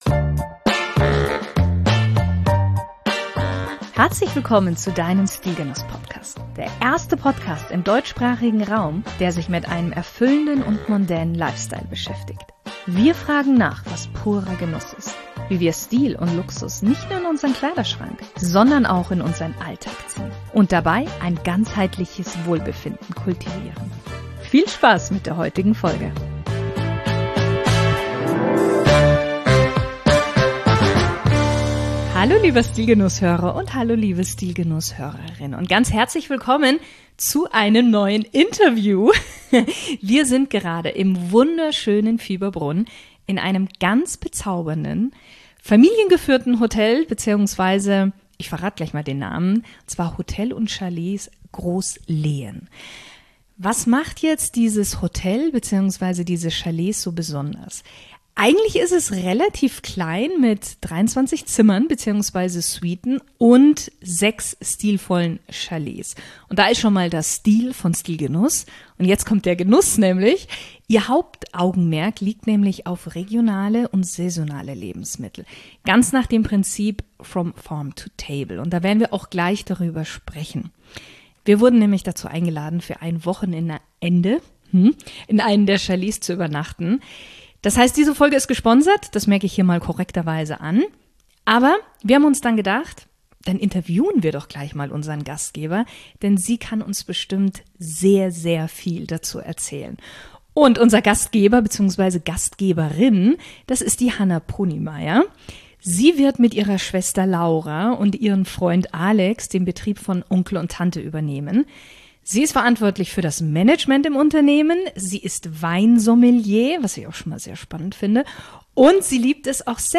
Wir reisen nach Fieberbrunn ins wunderschöne Tirol, wo wir im charmanten Hotel & Chalets Grosslehen zu Gast sind.